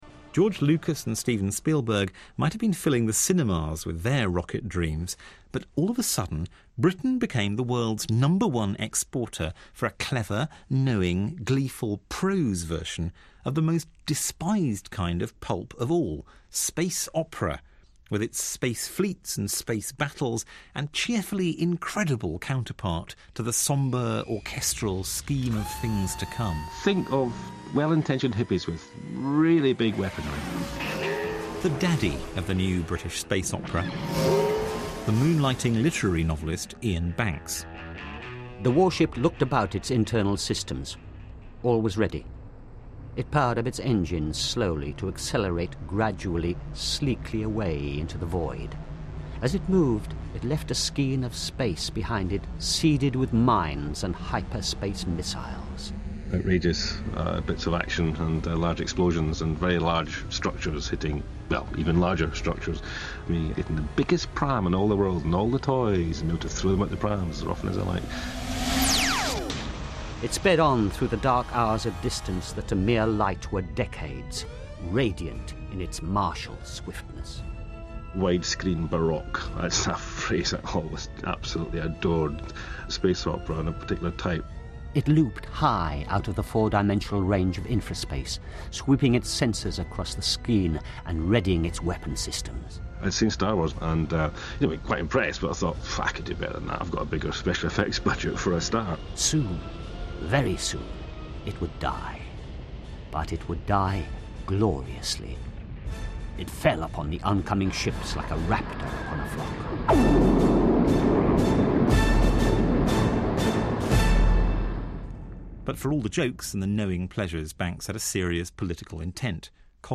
A. The Radio 4 'history of science-fiction' series 'Imagining Albion' featured Iain in two episodes, talking mainly about the Culture. It also featured some brief readings of his work.